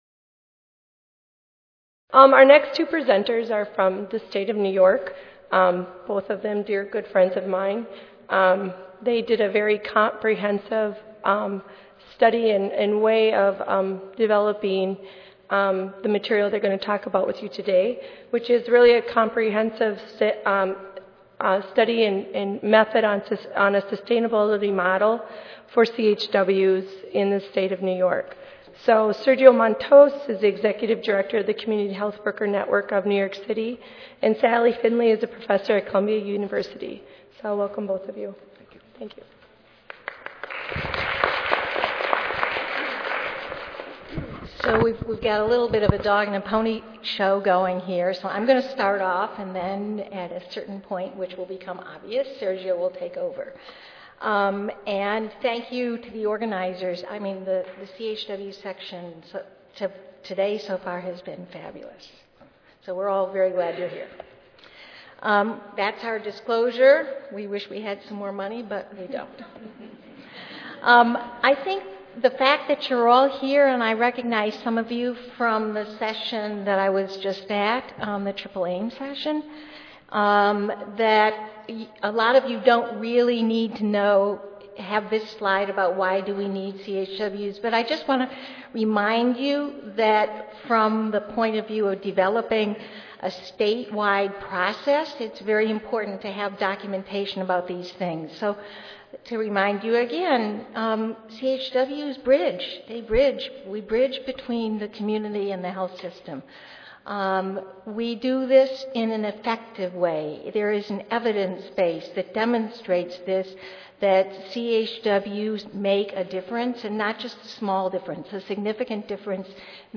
This work is co-authored and presented by a CHW.